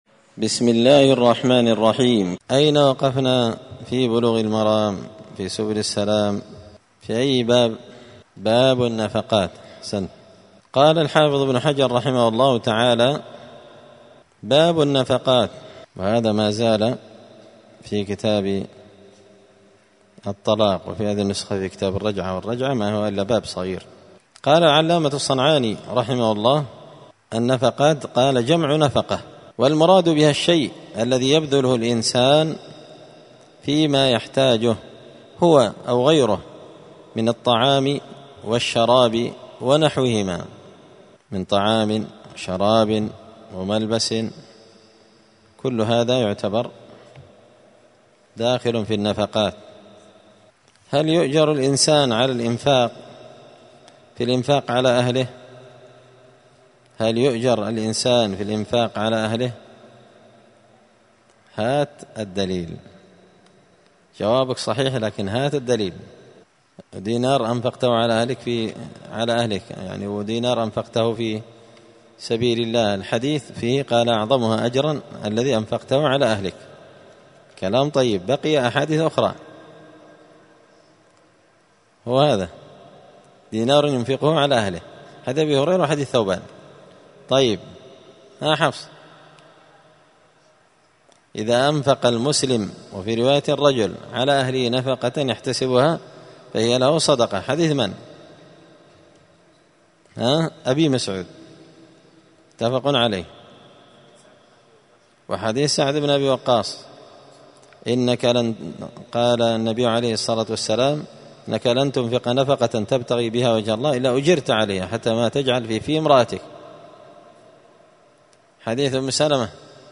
*الدرس الرابع والثلاثون (34) {باب النفقات}}*